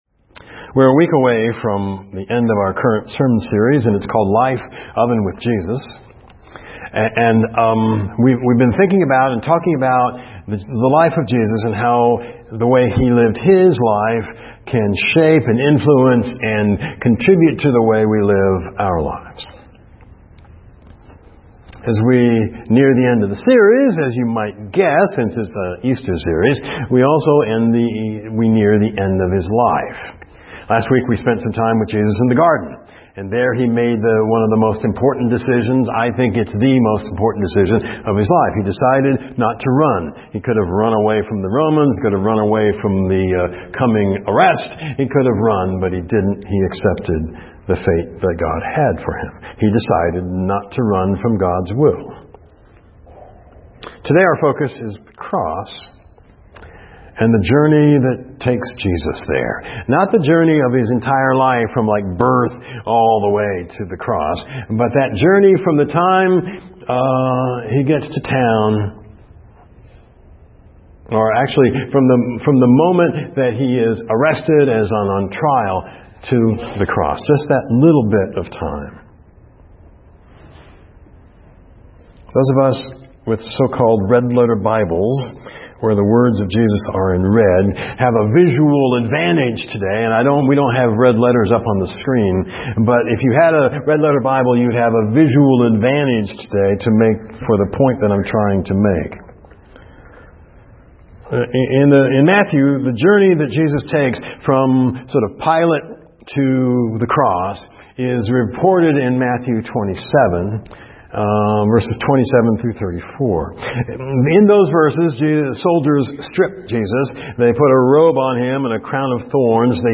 FCCEM Sermon Audio Files - First Christian Church of East Moline